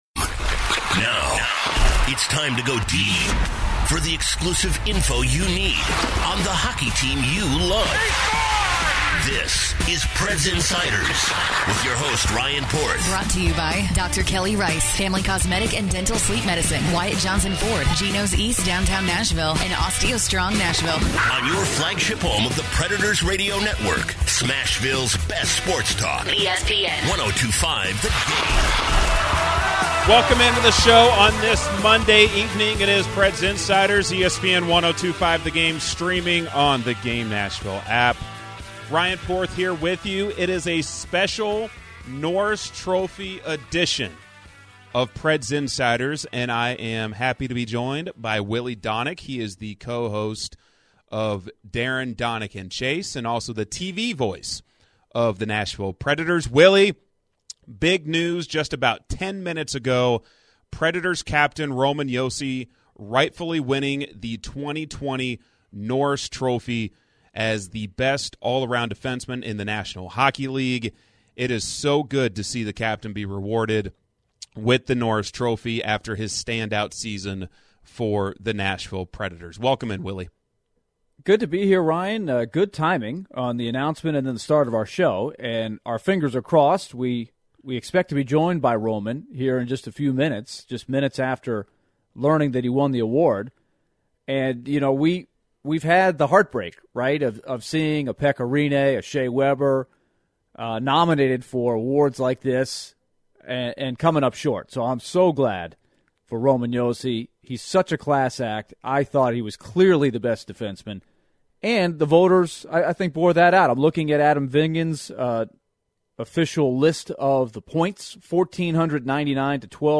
- 6:30, Josi joins the show live for his first interview after winning the award.